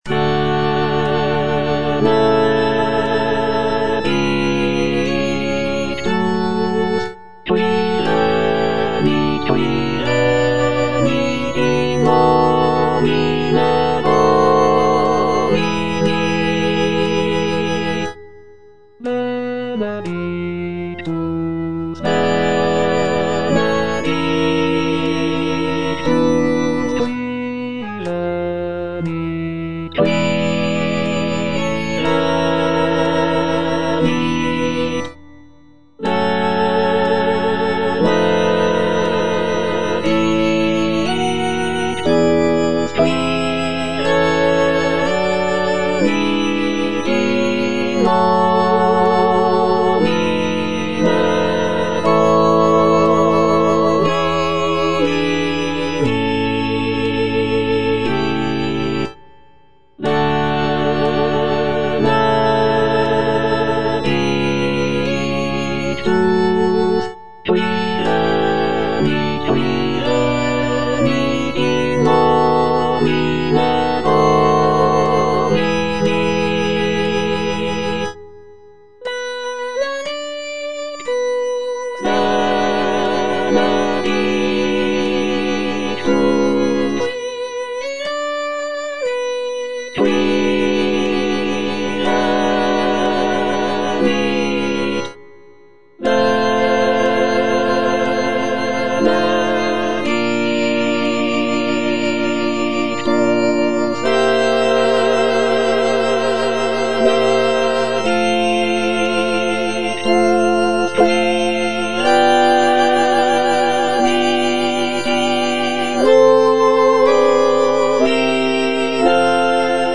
F. VON SUPPÈ - MISSA PRO DEFUNCTIS/REQUIEM Benedictus (tenor I) (Voice with metronome) Ads stop: auto-stop